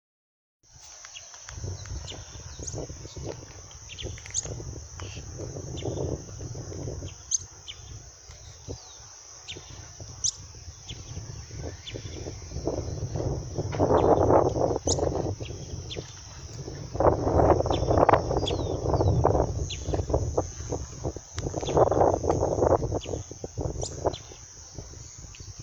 Canastero Coludo (Asthenes pyrrholeuca)
Nombre en inglés: Sharp-billed Canastero
País: Argentina
Provincia / Departamento: La Pampa
Condición: Silvestre
Certeza: Fotografiada, Vocalización Grabada